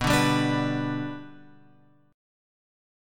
B Minor Sharp 5th